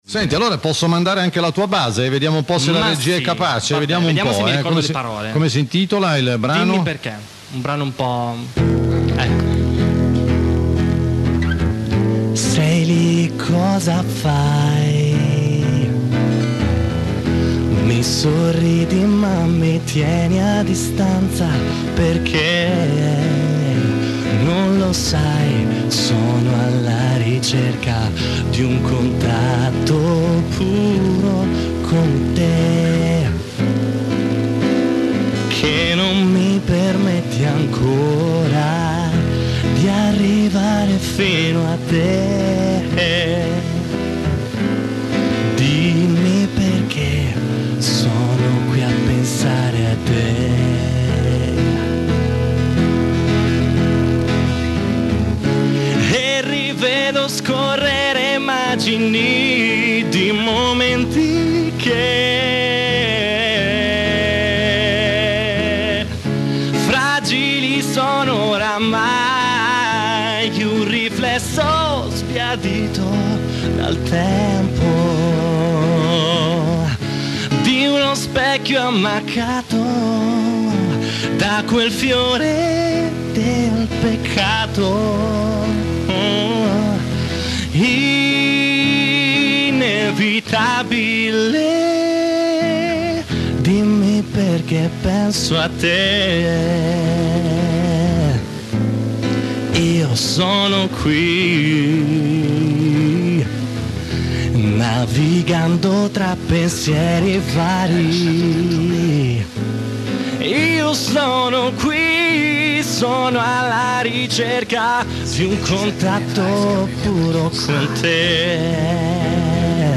cantare in radio dal vivo e accompagnato dalla chitarra